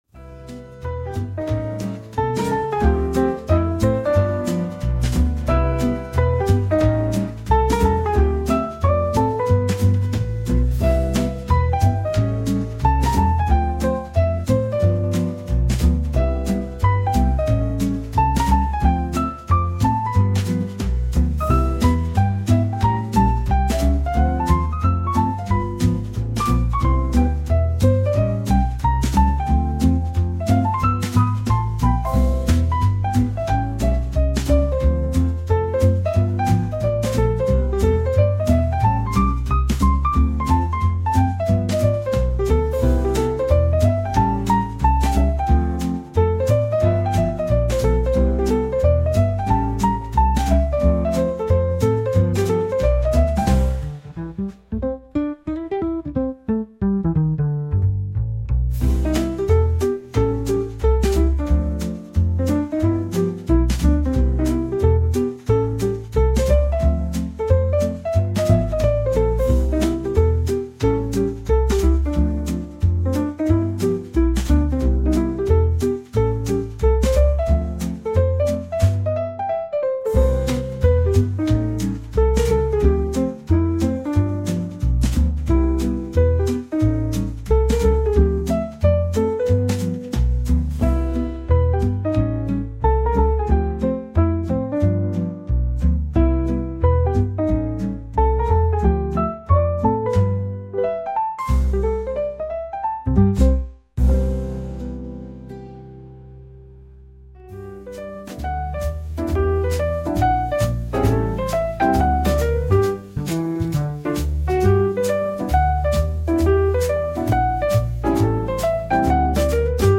こちらがレコード音質加工前の原音です
Lo-Fi jazz